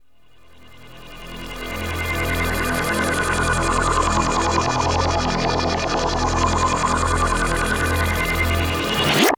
JUICYDRONE.wav